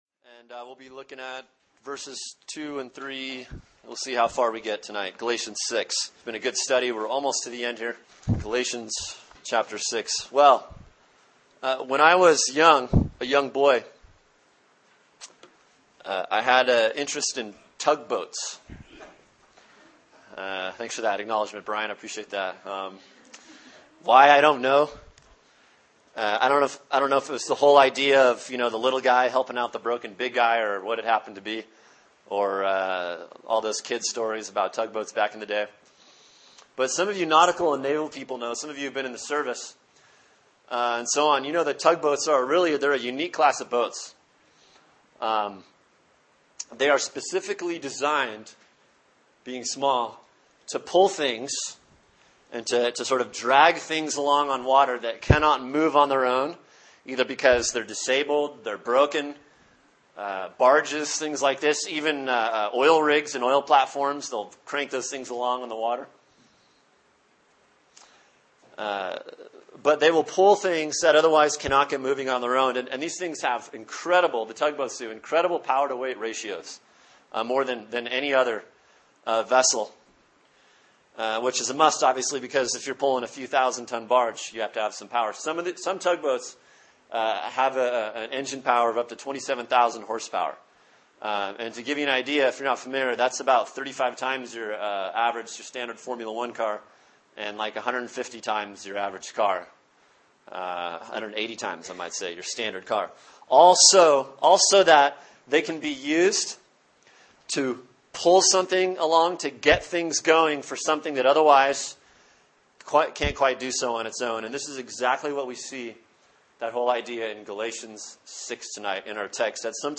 Sermon: Galatians 6:1-5 “Restoring One Another” Part 2 | Cornerstone Church - Jackson Hole